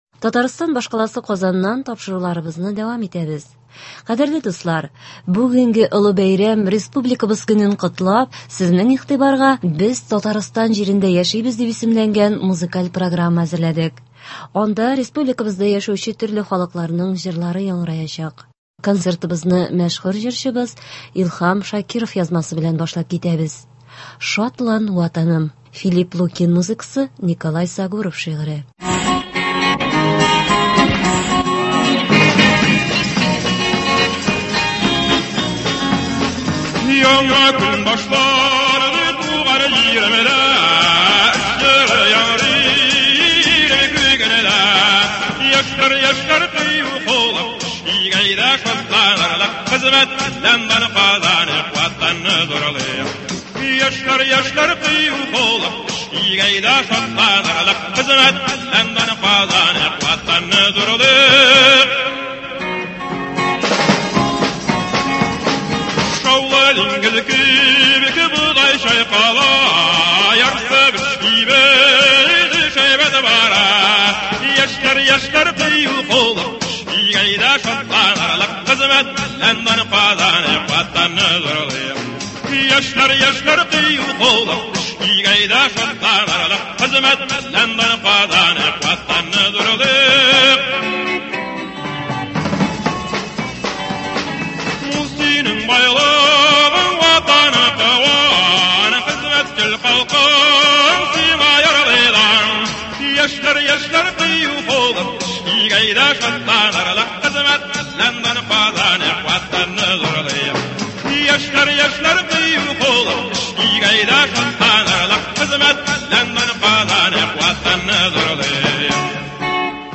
Кичке концерт (30.08.2021)